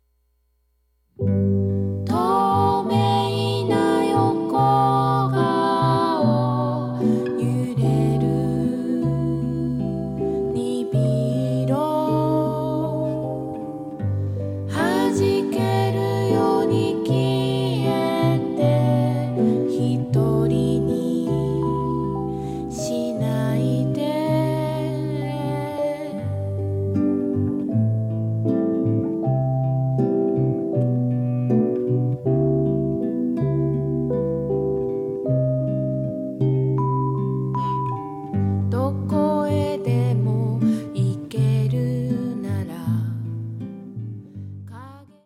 幻想の中に真理がある、童話のような歌たち。
サイケデリックであるけれど、アングラ過ぎない。